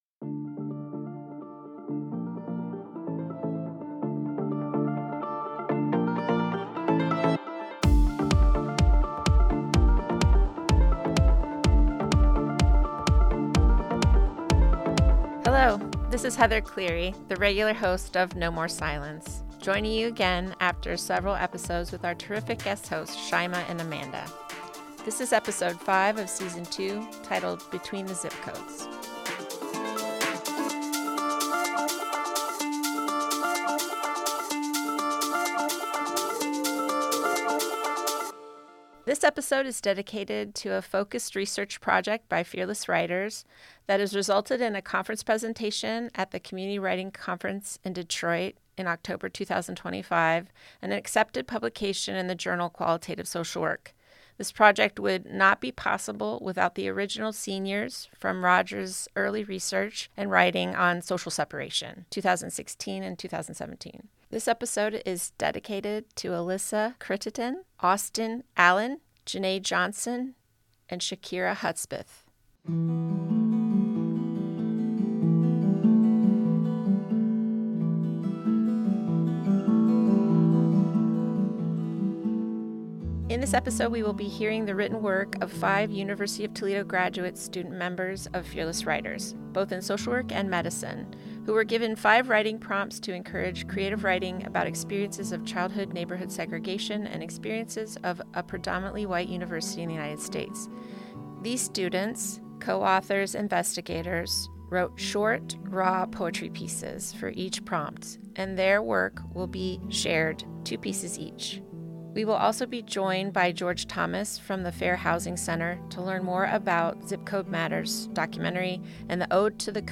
/*-->*/ /*-->*/ In this episode we will be hearing from University of Toledo graduate student members of a Fearless Writers both in social work and medicine who were given five writing prompts to encourage creative writing about experiences of childhood neighborhood segregation and experiences of a predominantly white university in the United States. These students wrote short, raw poetry pieces for each prompt and today will be sharing 2 pieces each.